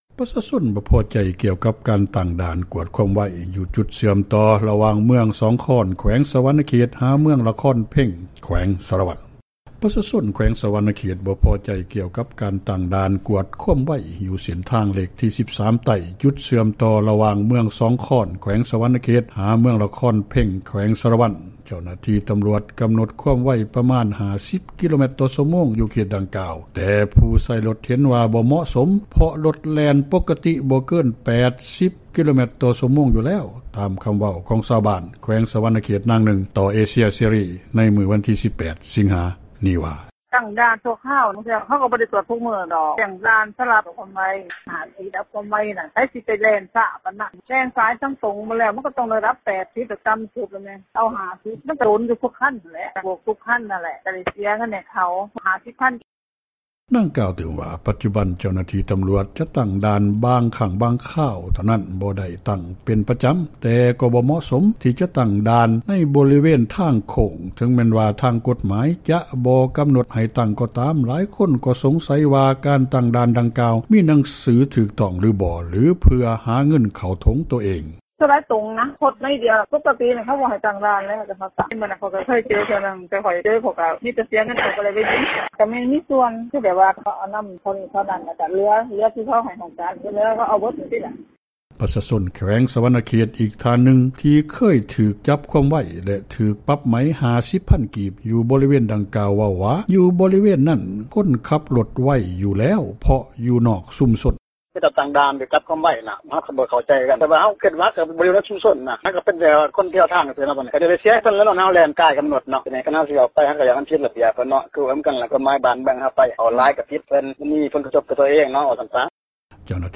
ຕາມຄຳເວົ້າຊາວບ້ານ ແຂວງສວັນນະເຂດ ນາງນຶ່ງ ຕໍ່ວິທຸຍເອເຊັຽເສຣີ ໃນມື້ວັນທີ 18 ສິງຫາ ນີ້ວ່າ: